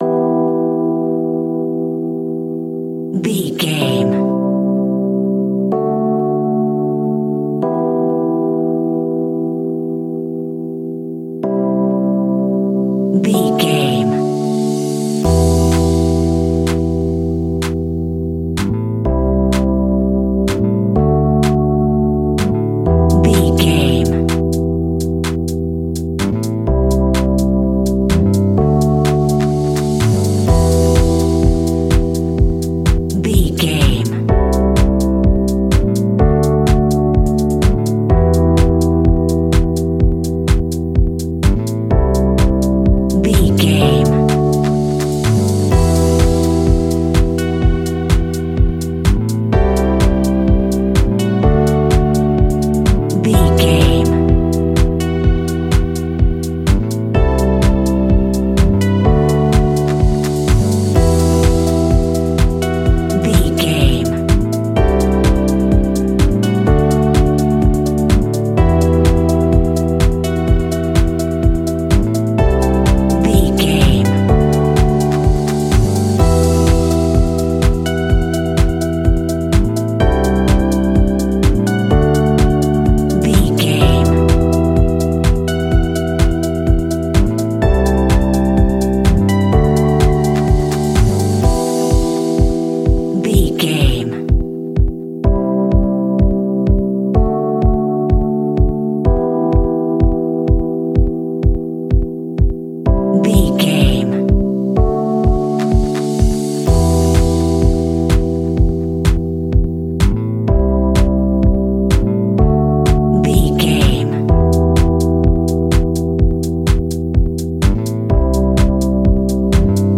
Aeolian/Minor
uplifting
energetic
bouncy
funky
bass guitar
synthesiser
electric piano
drum machine
funky house
deep house
nu disco
groovy
upbeat